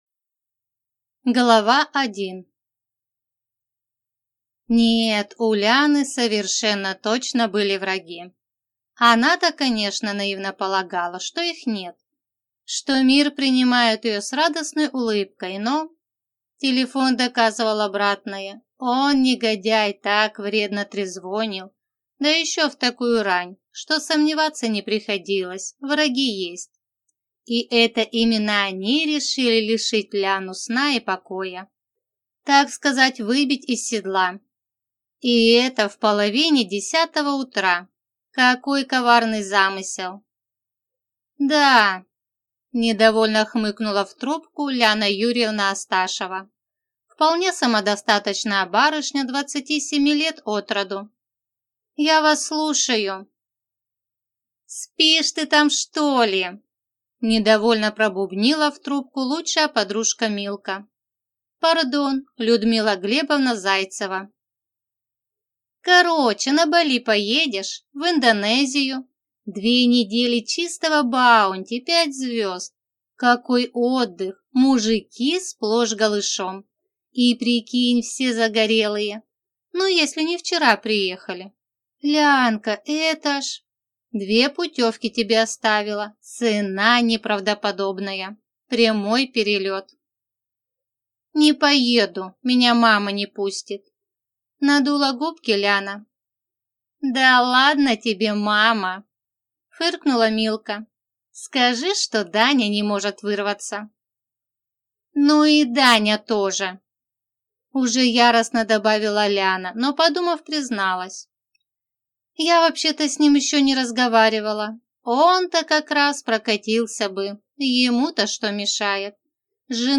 Аудиокнига Альфонс ошибается однажды | Библиотека аудиокниг
Прослушать и бесплатно скачать фрагмент аудиокниги